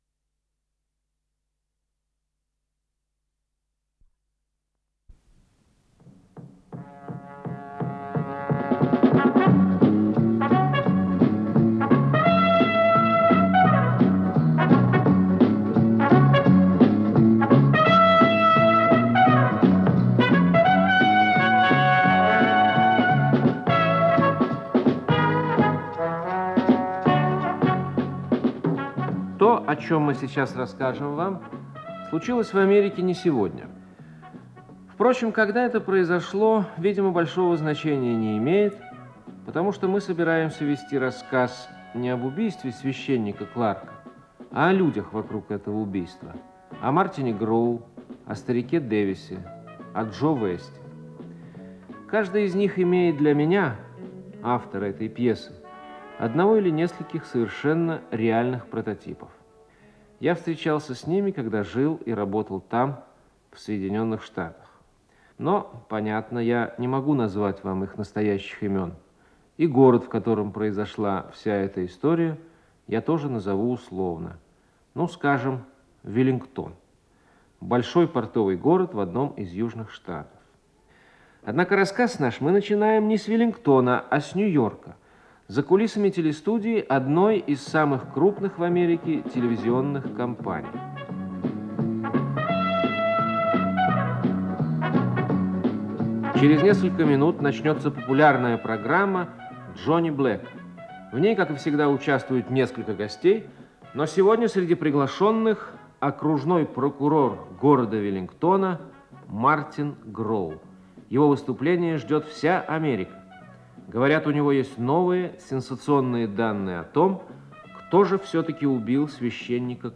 Исполнитель: Артисты театра им. В. Маяковского
Радиокомпозиция спектакля